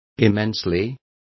Complete with pronunciation of the translation of immensely.